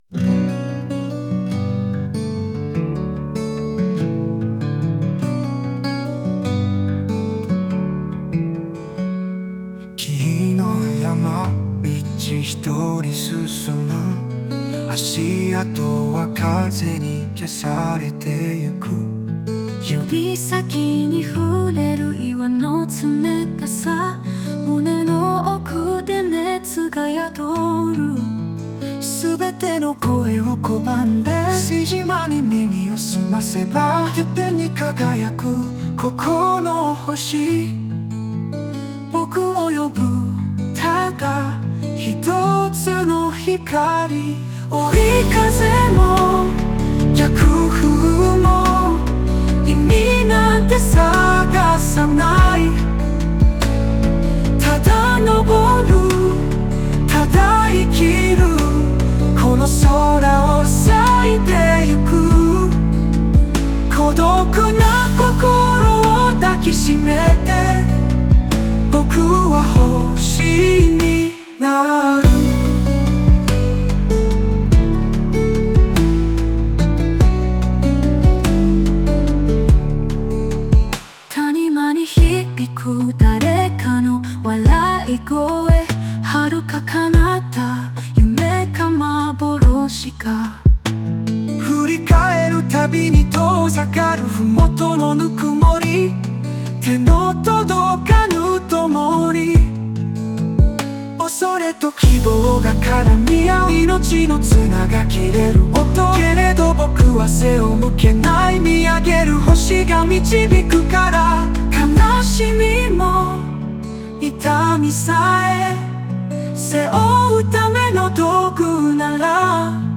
Music(音楽) Music(音楽) (1630)